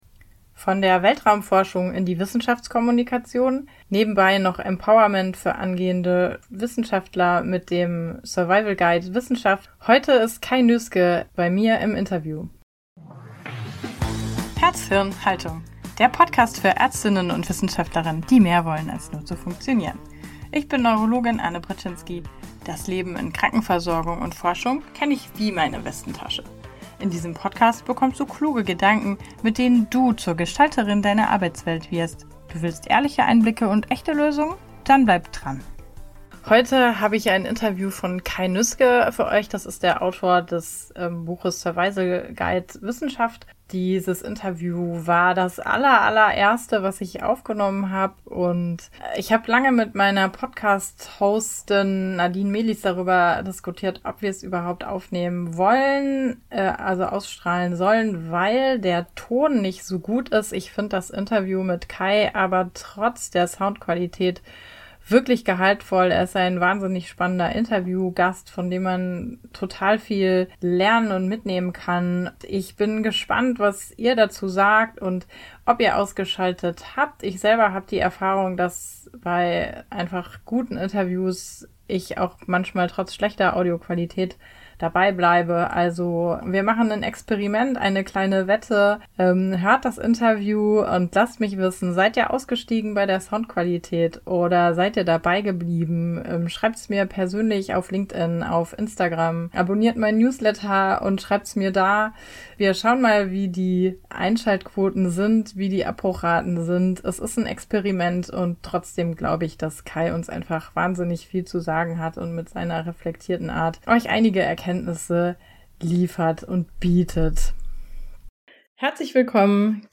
Hinweis: Die Tonqualität ist diesmal nicht optimal aber inhaltlich ist das Gespräch umso gehaltvoller.